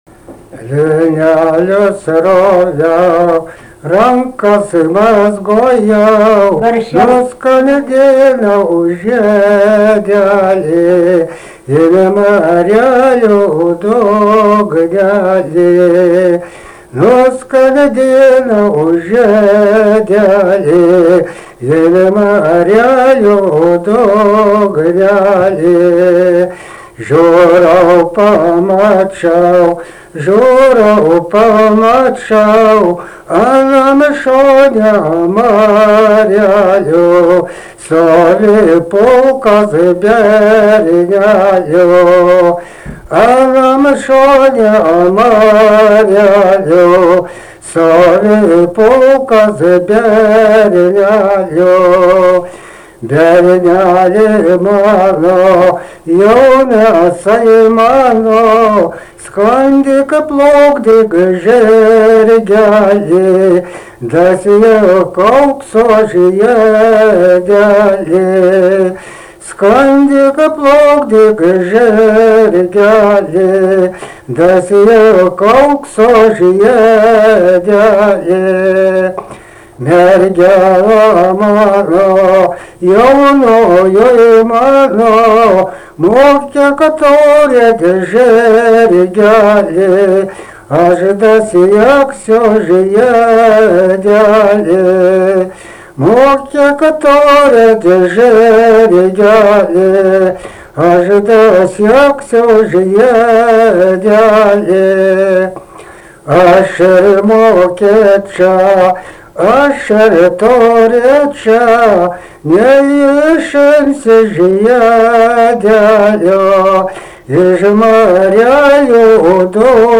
daina
Tameliai
vokalinis